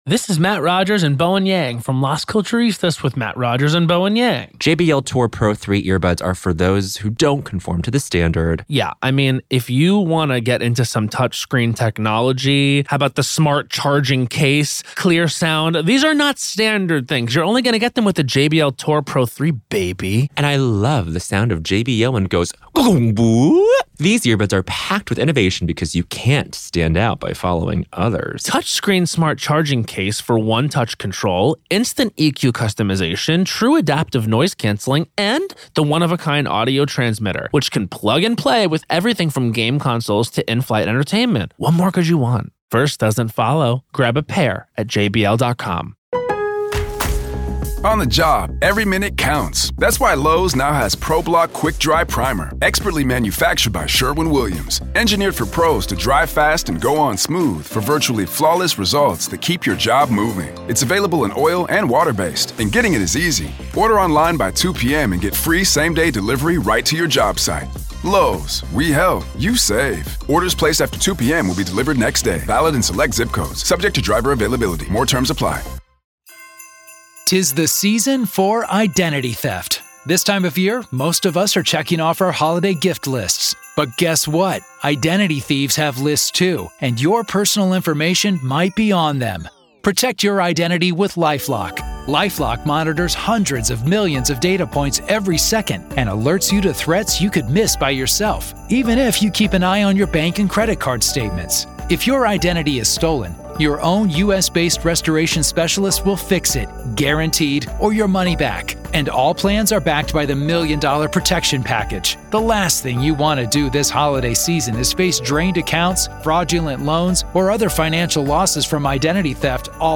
In this powerful hour-long conversation